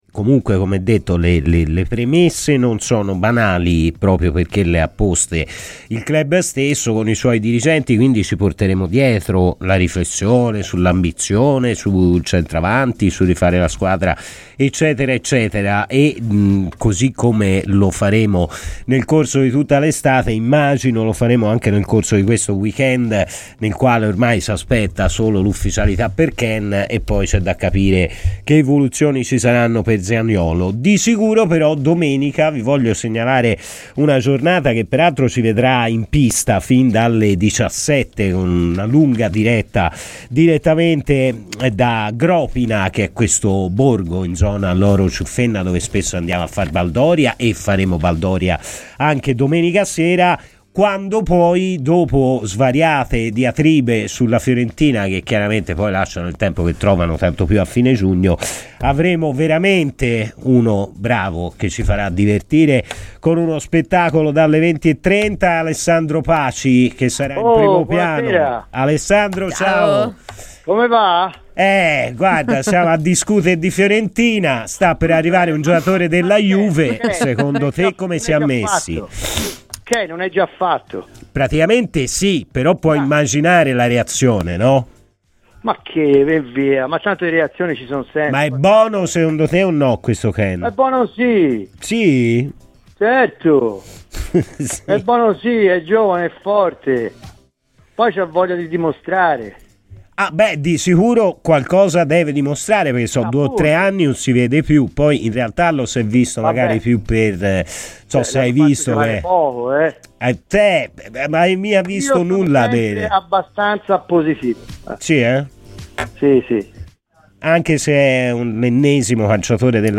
Il noto comico fiorentino, grande tifoso viola, Alessandro Paci, è intervenuto ai microfoni di Radio FirenzeViola, durante "Palla al Centro", per parlare dei temi di casa Fiorentina.